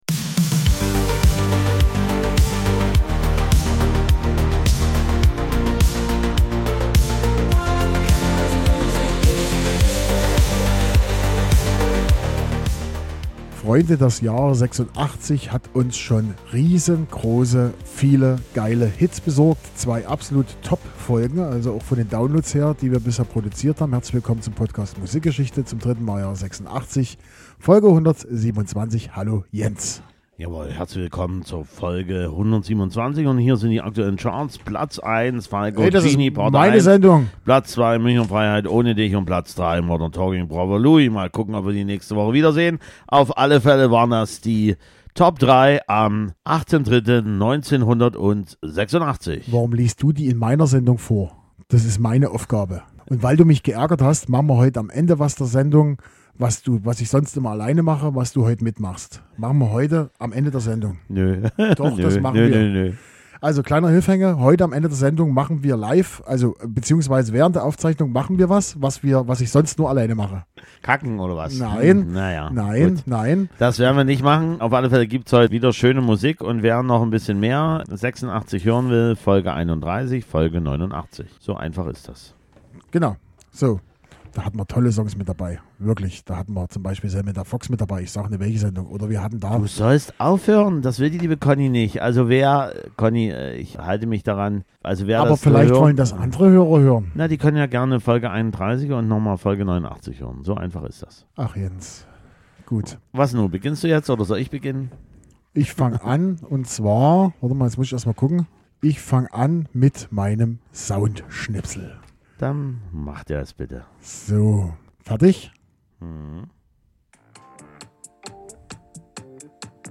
Musikalisch gibts die volle Bandbreite der 80er. Schlager, Funk, Pop und Reggae - voll die Abwechslung.